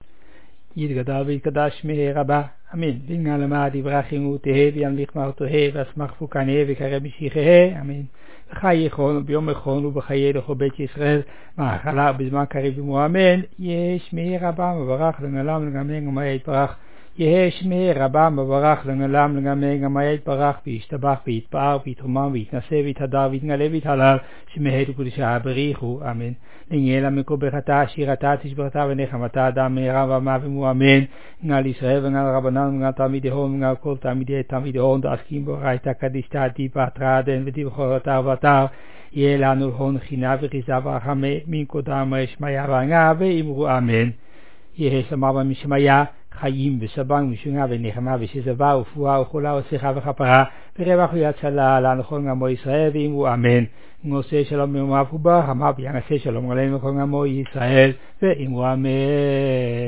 The service at the unveiling of a Matseva (tombstone) at Beth Haim in Ouderkerk aan de Amstel.
In contrast to the mitsva (levaya), there is no singing: all texts are recited.
Recited